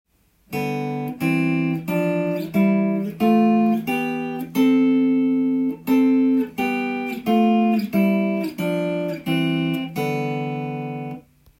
6度ハモリでスケールを弾いたTAB譜
４弦始まり